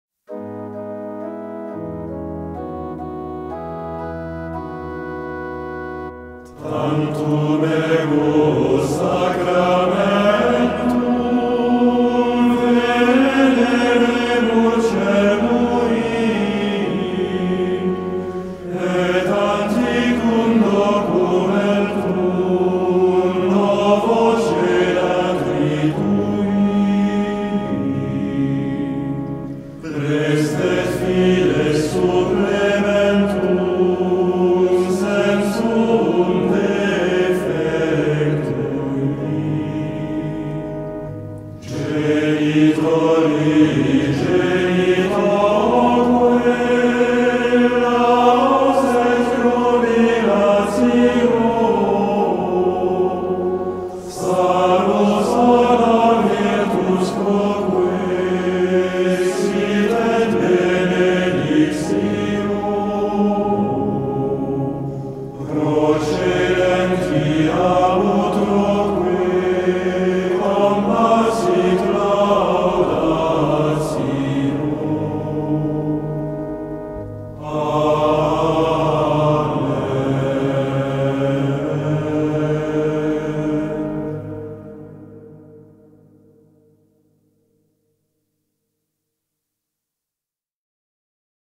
Chaque dimanche et fête la messe est célébrée à 9h selon la forme Tridentine
Lieu Eglise Saint-François-de-Paule (Fréjus)